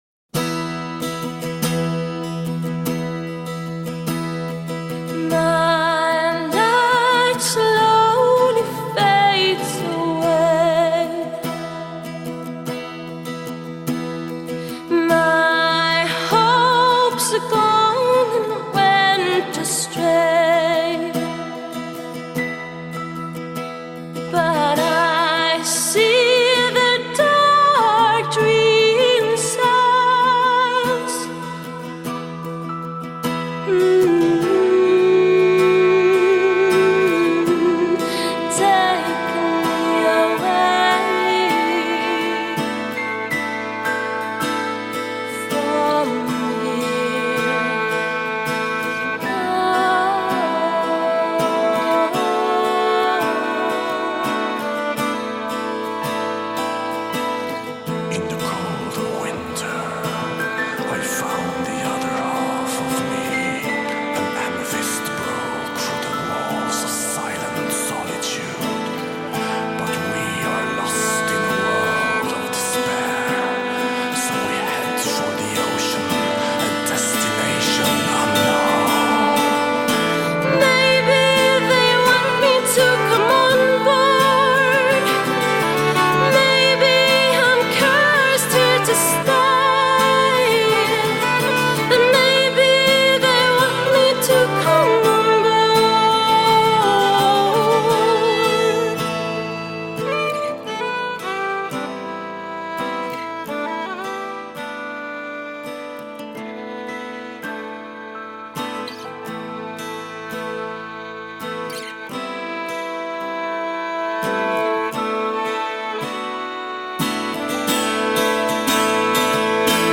Doom/Death Metal and Gothic Metal